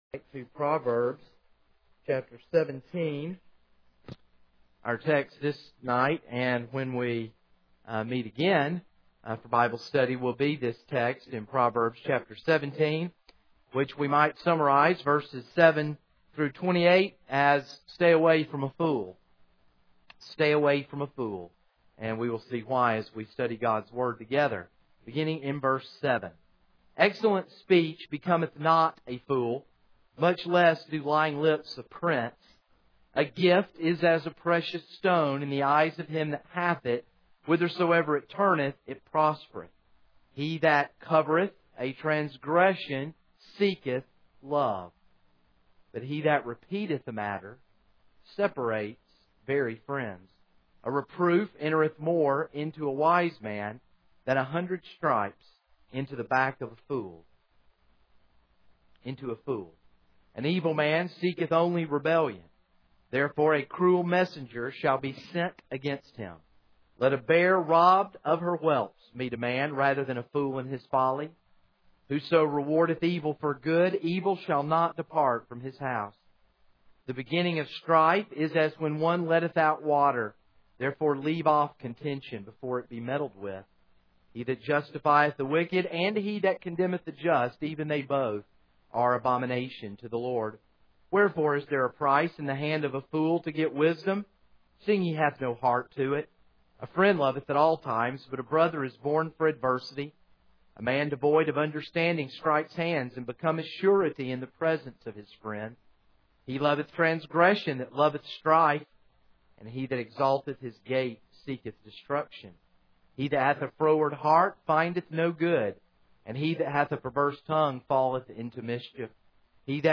This is a sermon on Proverbs 17:7-28.